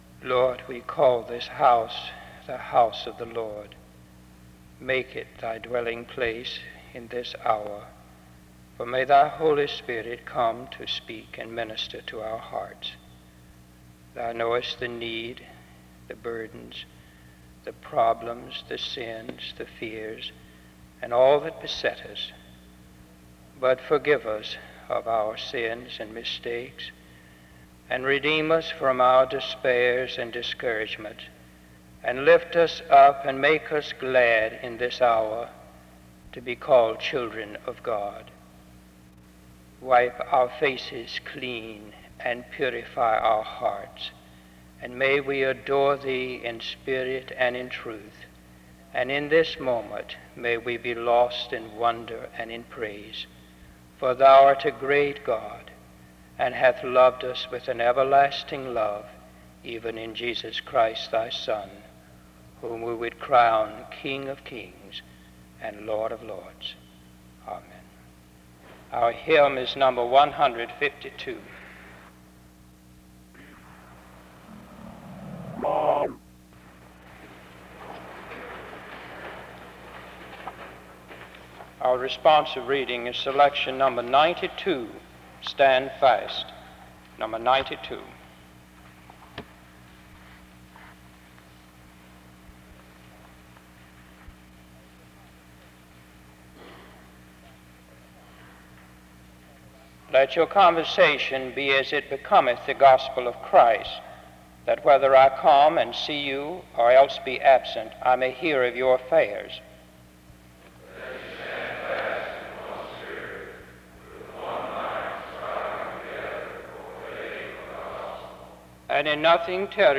The service opens with a prayer from 0:00-1:07. A responsive reading takes place from 1:22-3:16. The choir performs from 3:24-5:27.
He speaks against the temptation to covet the ministries of other pastors. The service closes with music from 7:09-17:55.